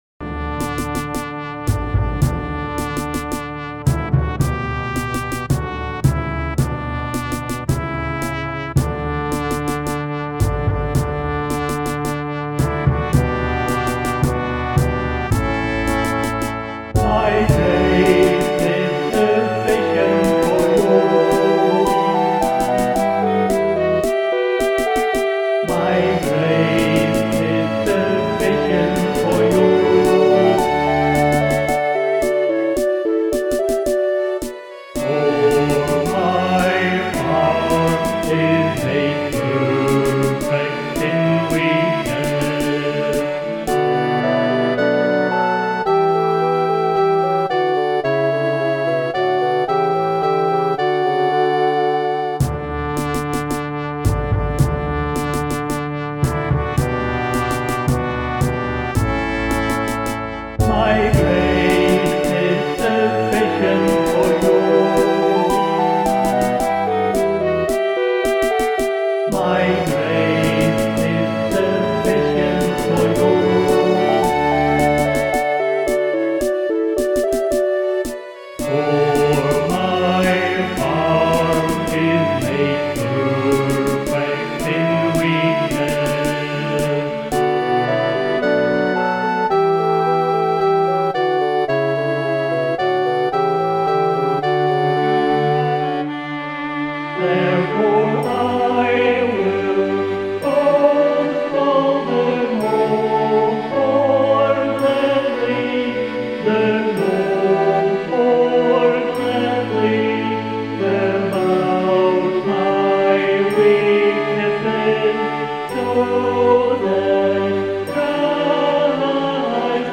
with a wonderful 12 part, full orchestral MIDI arrangement.